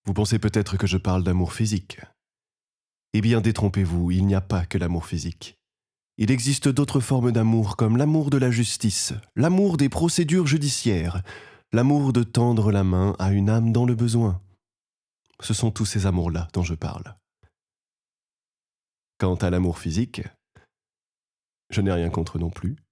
L'amour physique - Voix off
22 - 45 ans - Baryton